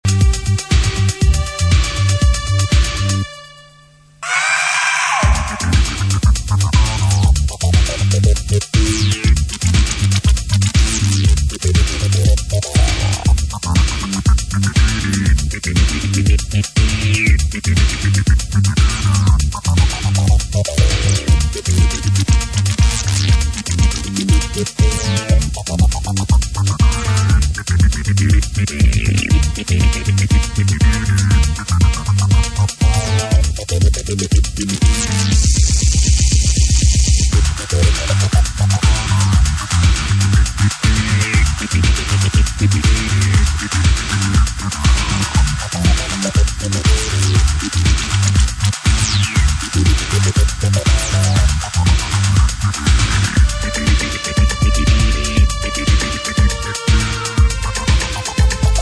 [90SHOUSE]